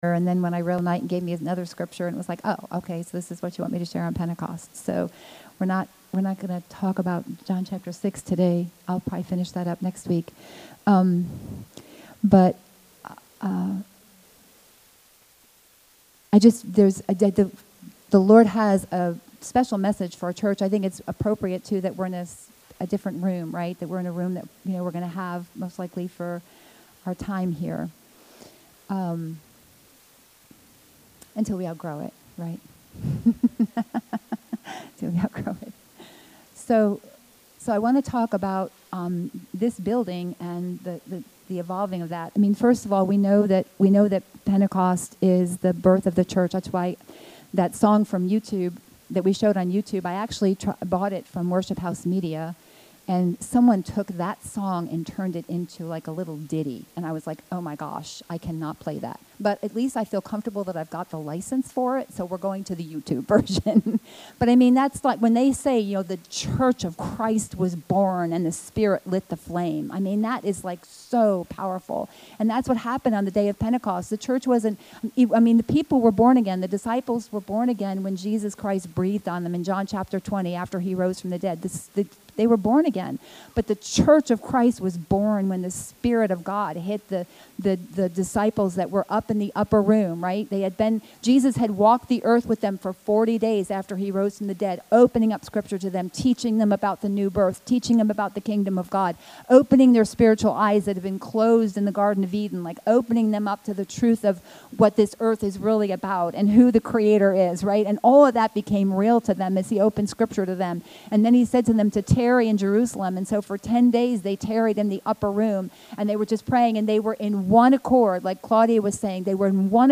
The Lord has given our church a charge - to come into the deeper waters of Ezekiel 47. In this Pentecost message